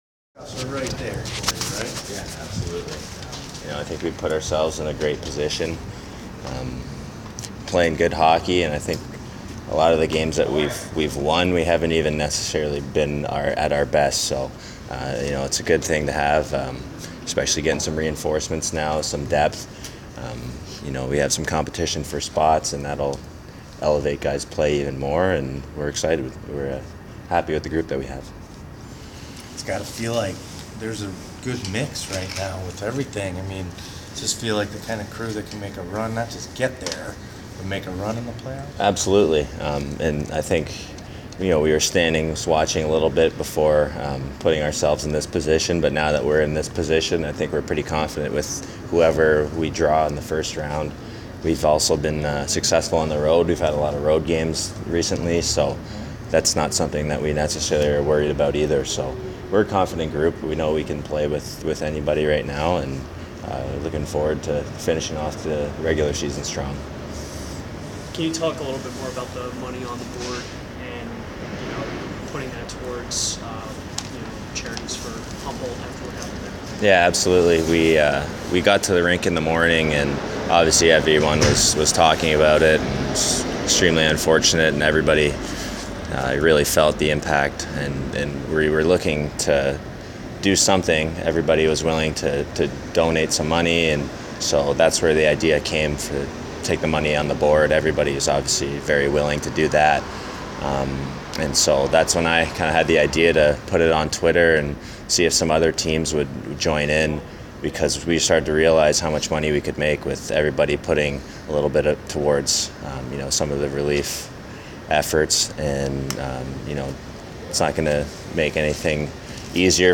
Monday, April 9th Media Day Audio